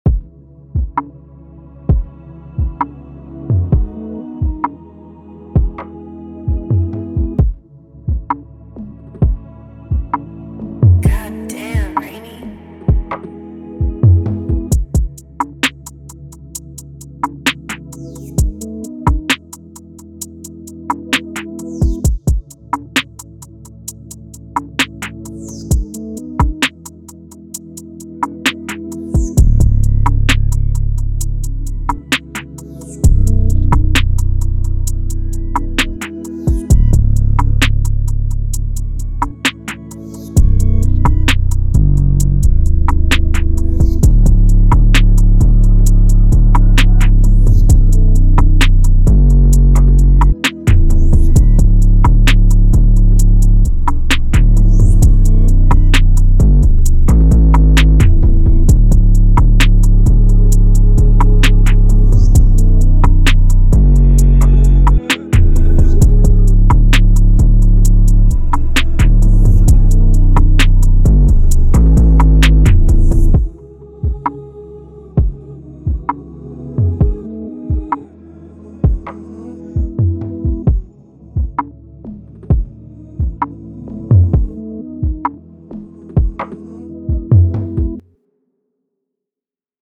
131BPM (RNB/ALTERNATIVE/RAP) CO